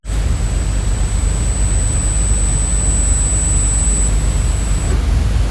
BruitElecPC.mp3